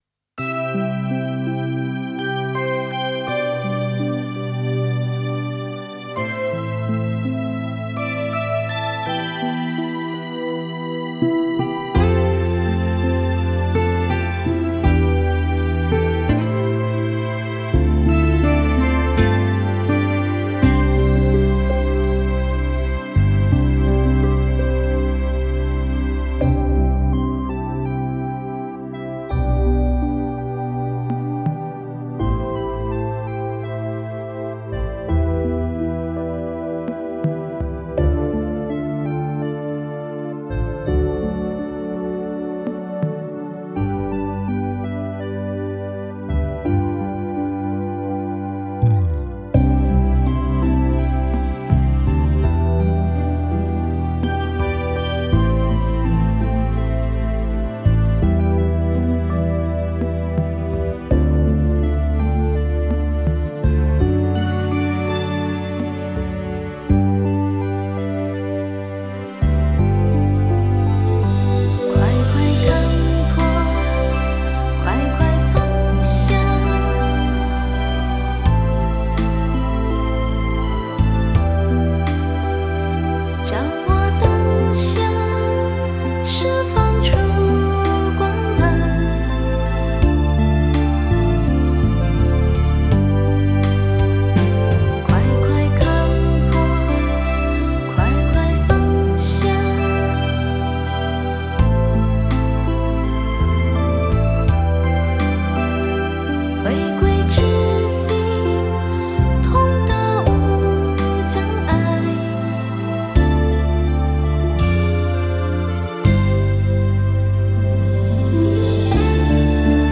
【类别】 音乐CD
清幽的梵唱